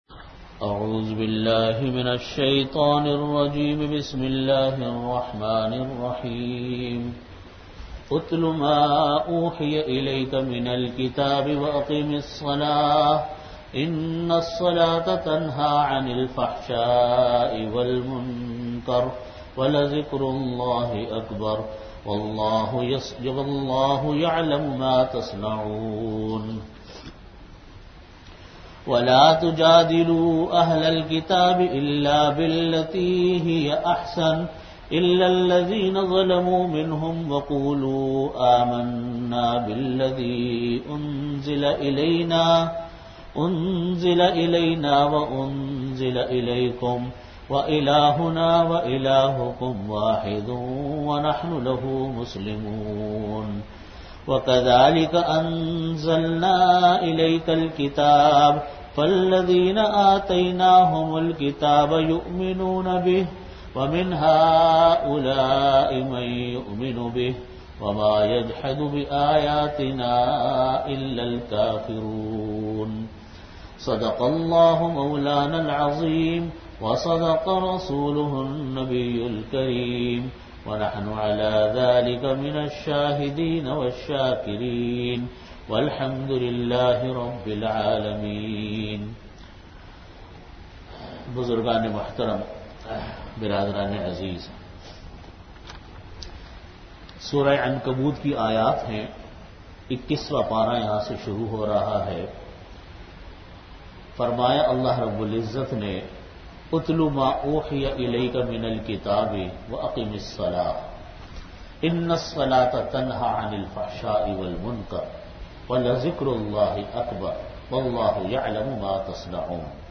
Delivered at Jamia Masjid Bait-ul-Mukkaram, Karachi.